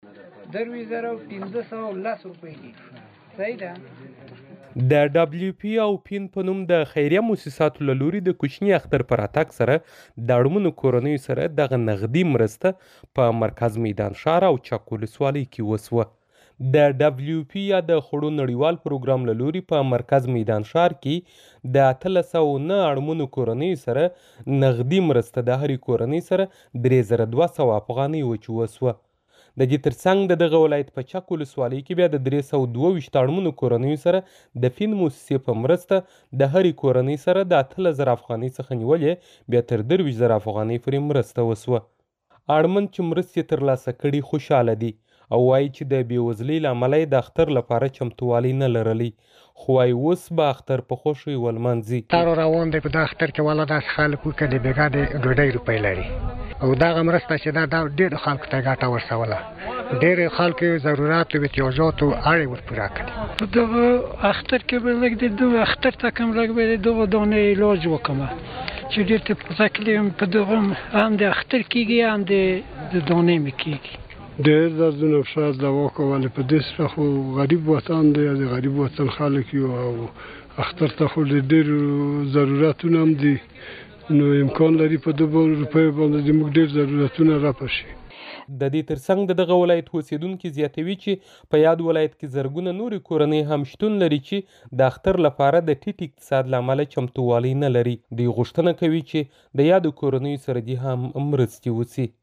د میدان وردګ راپور